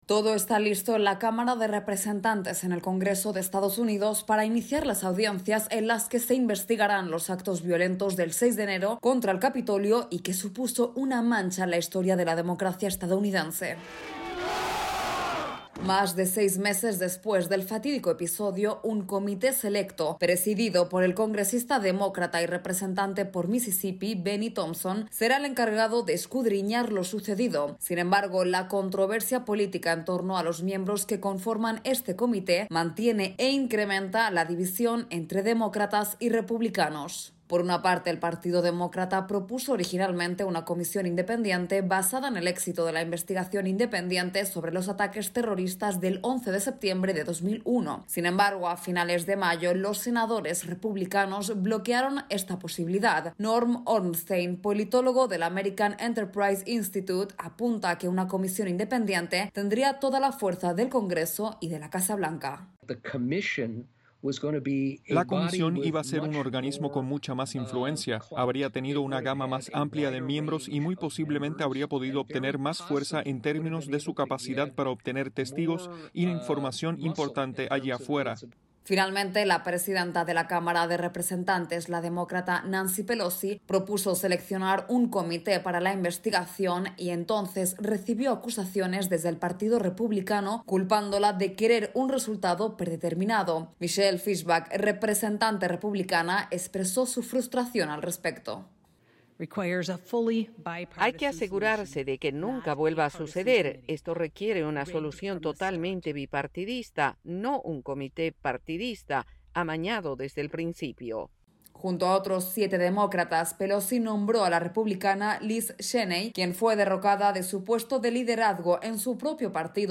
Una gran polémica envuelve al Comité selecto en la Cámara de Representantes de Estados Unidos que investigará los actos violentos del 6 de enero contra el Capitolio y hoy inicia sus audiencias. Informa